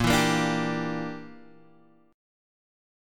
A#6b5 chord